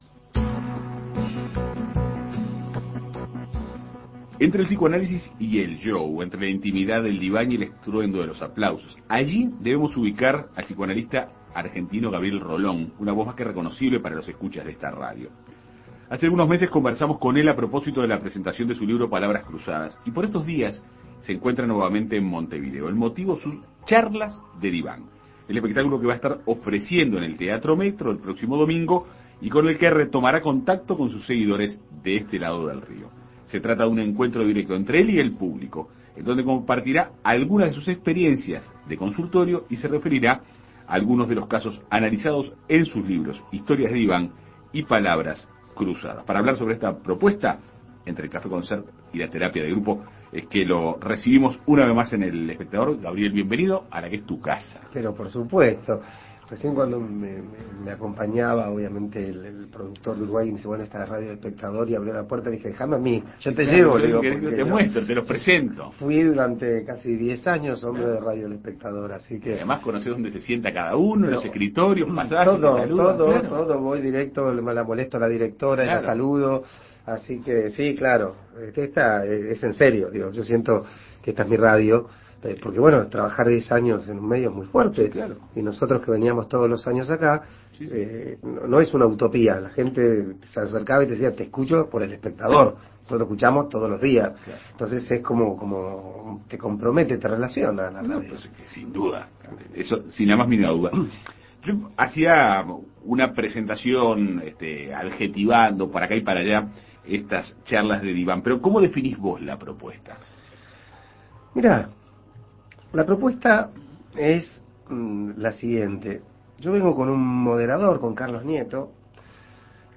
Se trata de un encuentro directo con el público, en donde compartirá algunas de sus experiencias en el consultorio y se referirá a los casos analizados en sus libros Historias de Diván y Palabras Cruzadas. En Perspectiva Segunda Mañana dialogó con él.